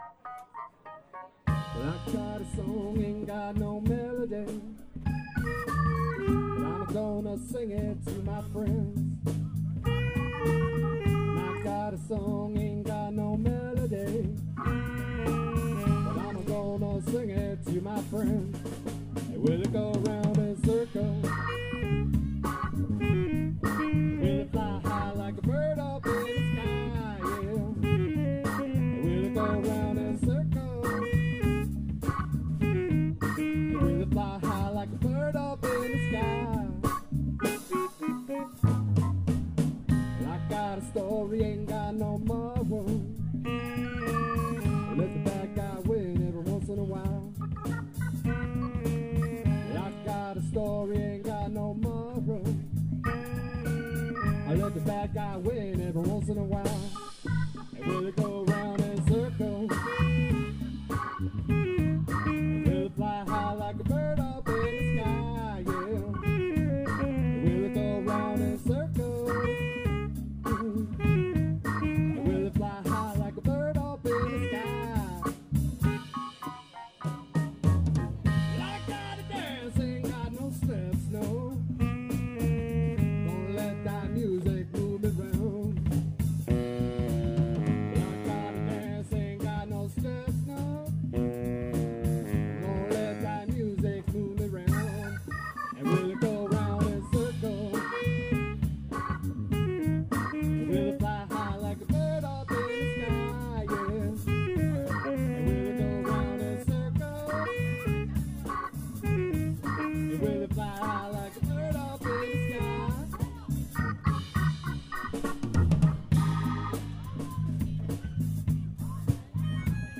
Abm